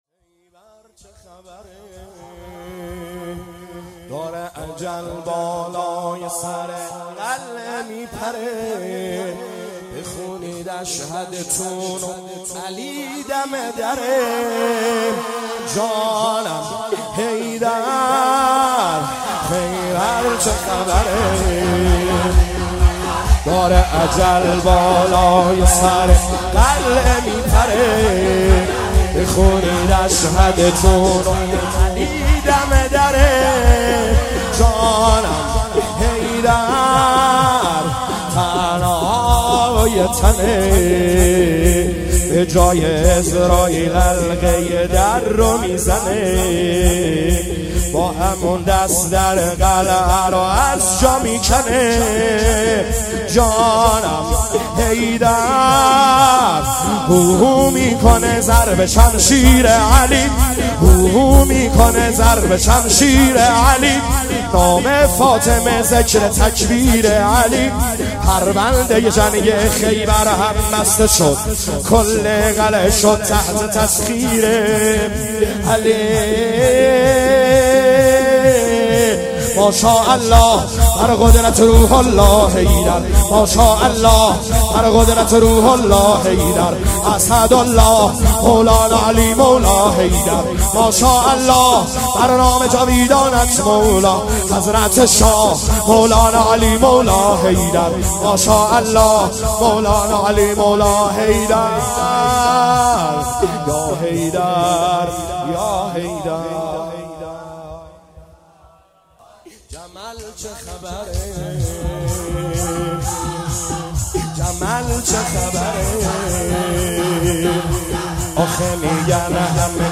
شور
عزاداری شهادت حضرت امیرالمومنین علیه السلام شب بیستم ماه مبارک رمضان - شب اول - 1401